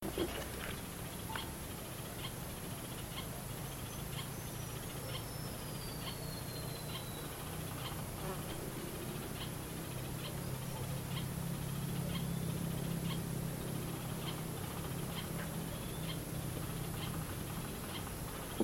Rufous-capped Spinetail (Synallaxis ruficapilla)
110722_021-Pijui-Corona-Rojiza.mp3
Life Stage: Adult
Location or protected area: Parque Provincial Araucaria
Condition: Wild
Certainty: Observed, Recorded vocal